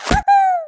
1 channel
kids_match.mp3